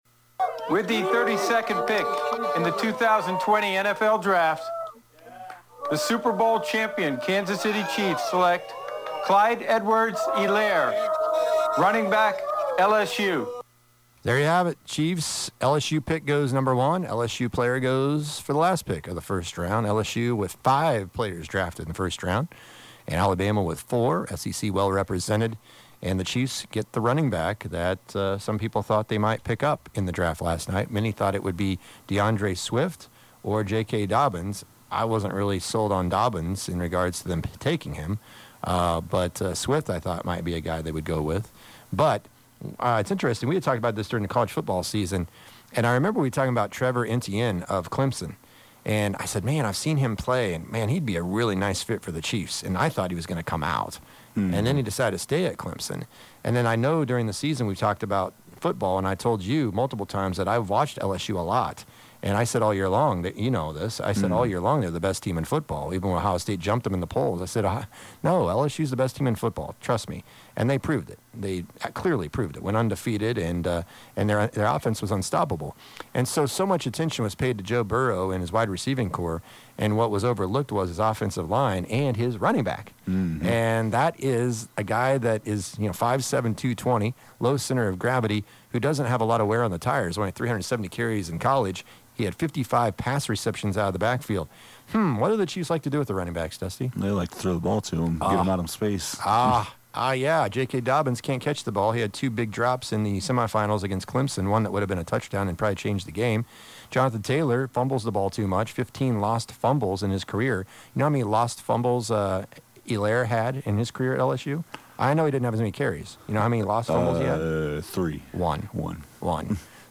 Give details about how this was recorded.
2012 Sub-State Girls Basketball Final: We replay the 4th qtr of Beloit vs TMP